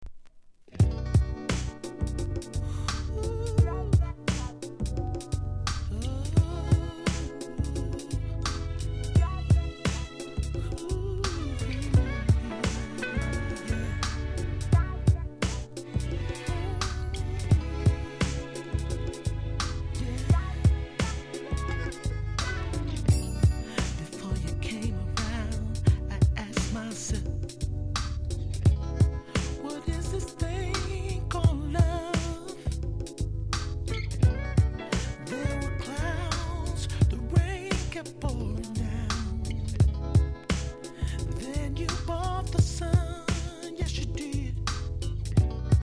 HIP HOP/R&B
エモーショナルな歌声で聴かせるSLOWナンバー!